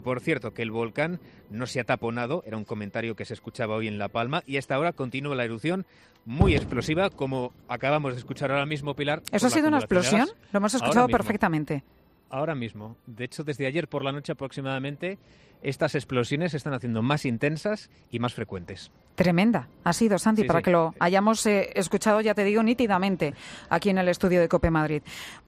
narra en directo una gran explosión del volcán de La Palma